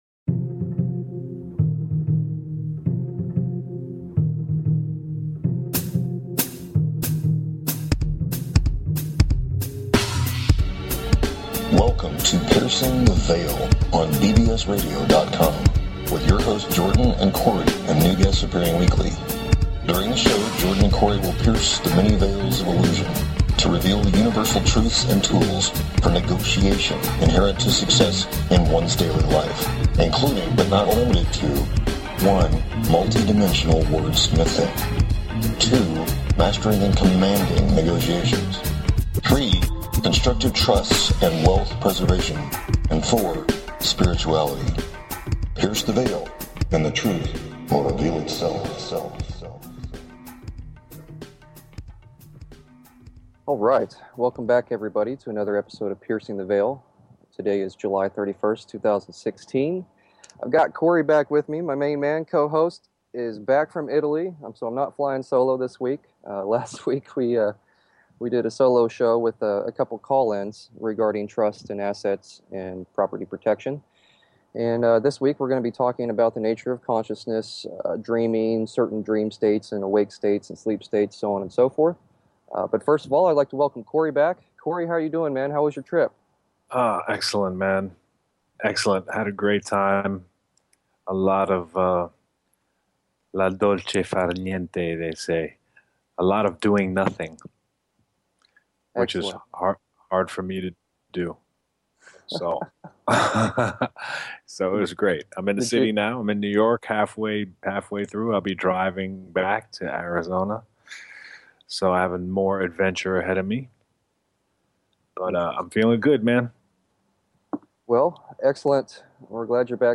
What started as a banned youtube channel (in less than one week,) has developed into a full blown Weekly Radio Show.